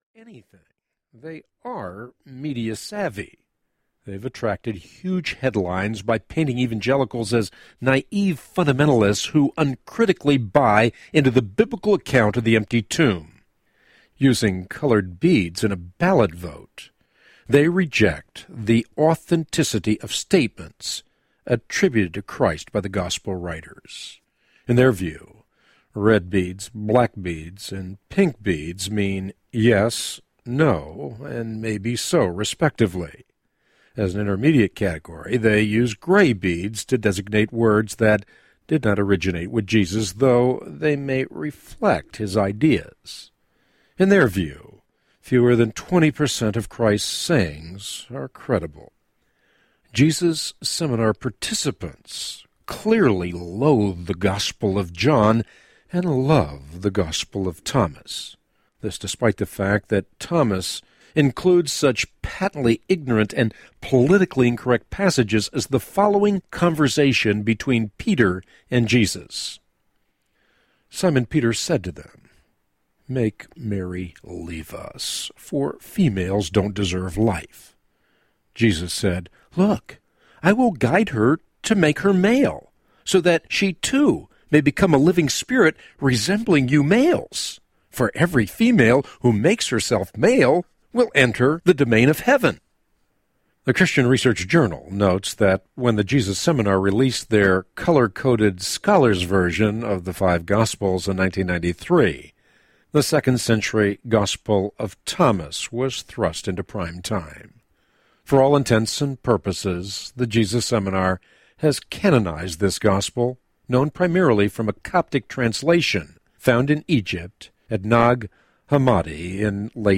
The Third Day Audiobook